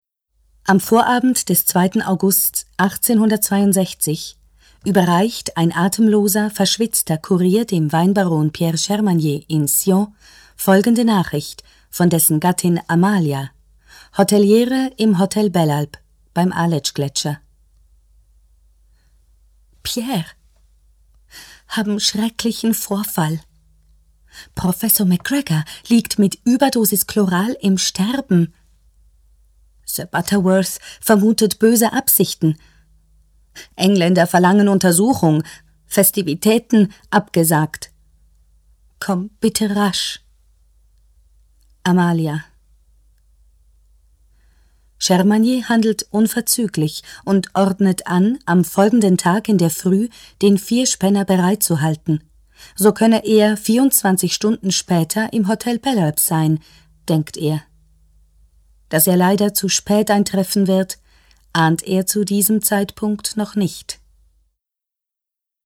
Hörbuch, 4 CD, 145 Min